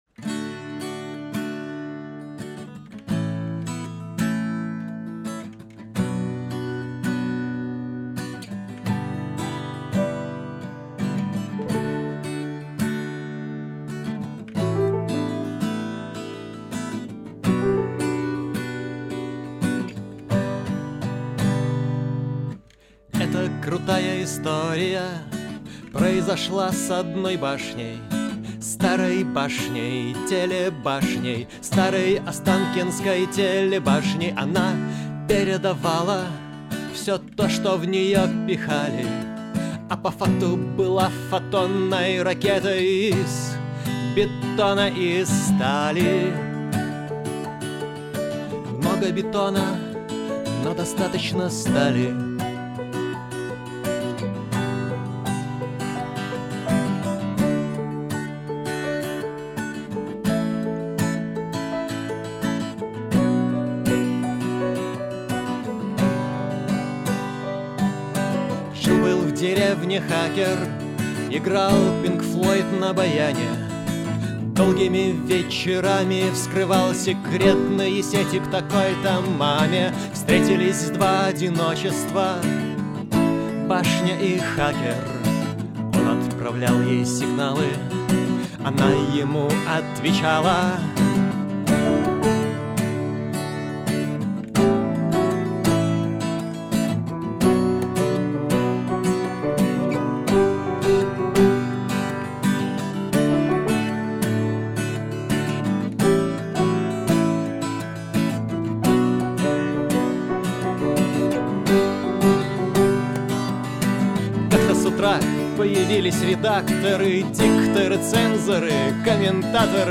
Вот, кстати, демка одной песни - почти про позывной: